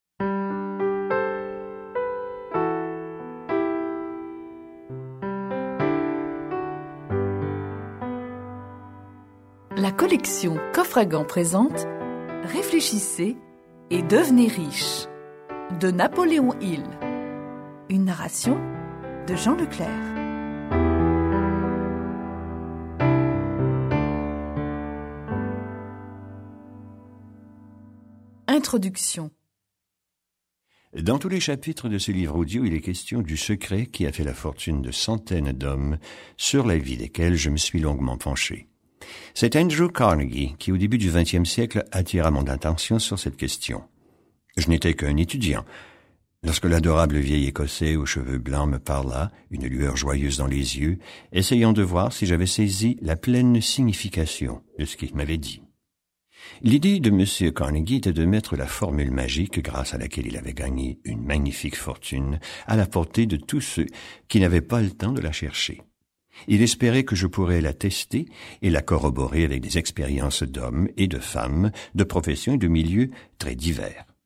Réfléchissez et devenez riche - Napoléon Hill - Voici un livre audio qui peut transformer votre vie.